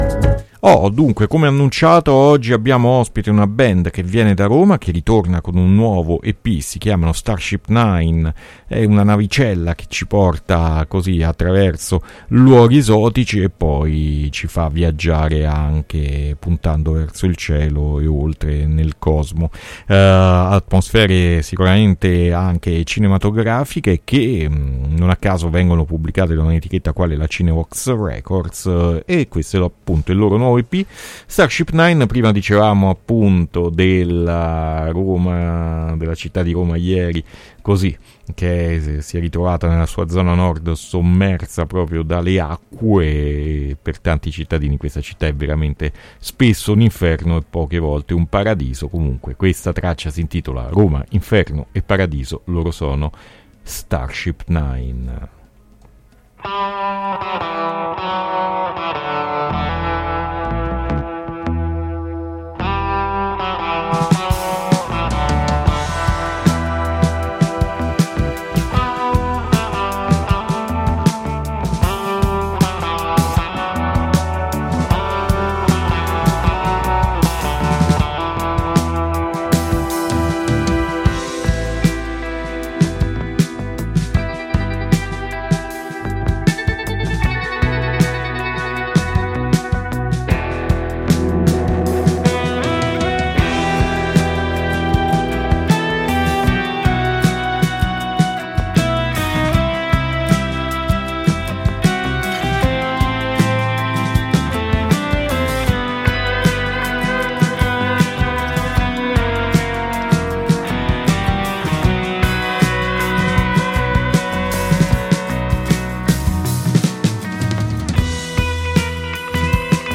Intervista Starship 9 a Mercoledì Morning 9-6-2021